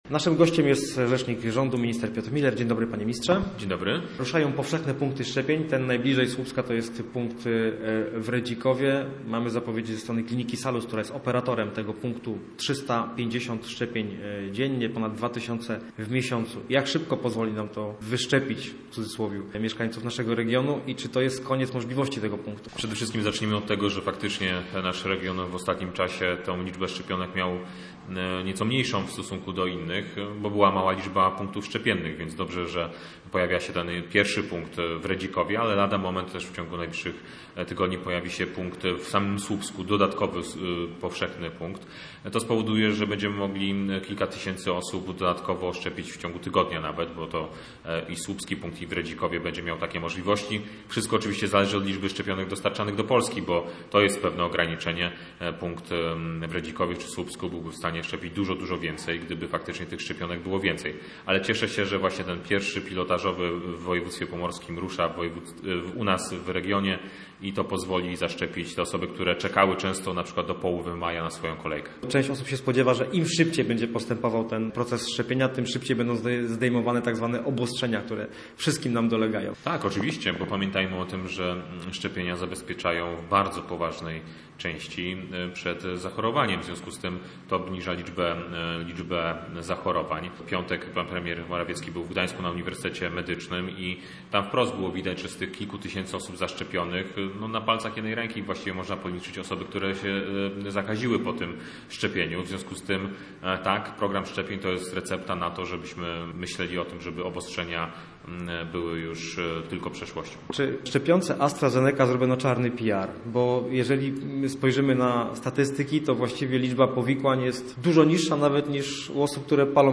Minister Piotr Müller przybliżył temat będąc gościem miejskiego programu Radia Gdańsk Studio Słupsk.